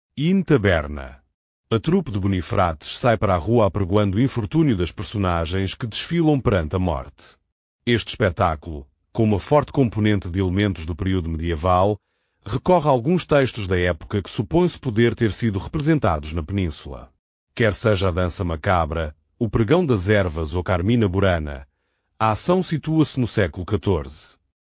este áudio guia possui 13 faixas e duração de 00:19:08, num total de 13.6 Mb